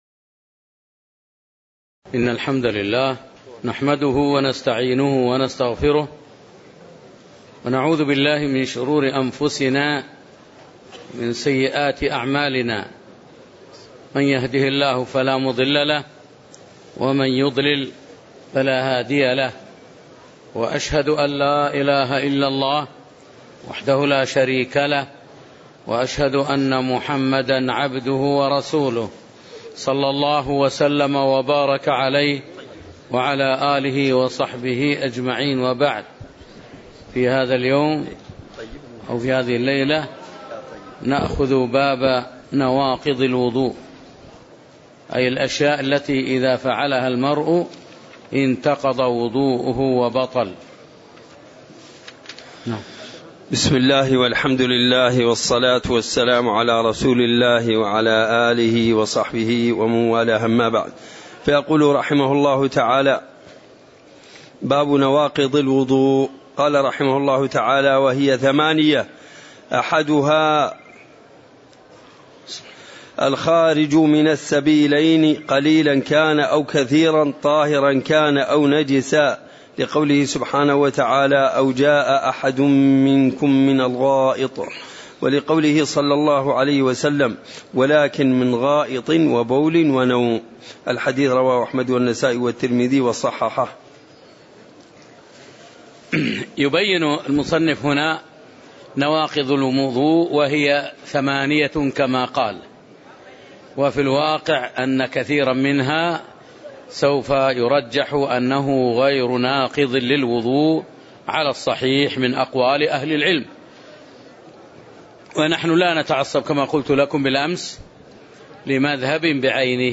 تاريخ النشر ١٠ رجب ١٤٣٨ هـ المكان: المسجد النبوي الشيخ